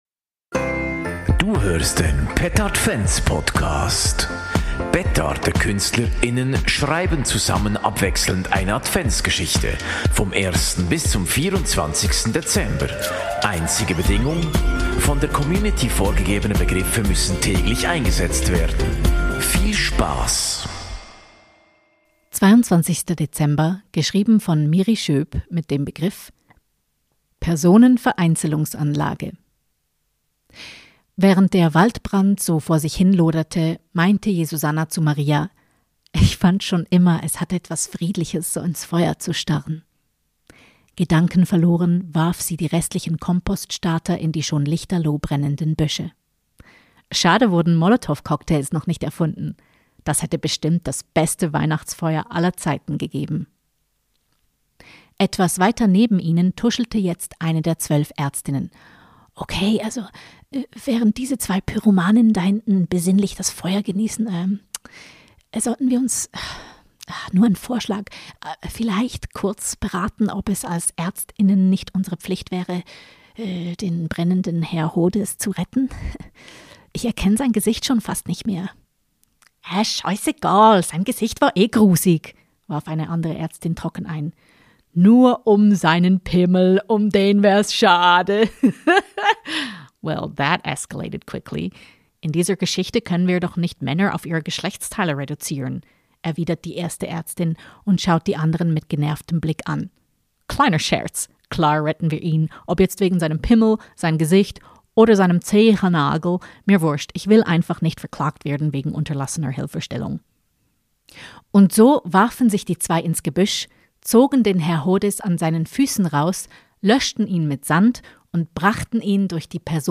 Petardvent Podcast ist ein Adventspodcast der Satiremarke Petarde.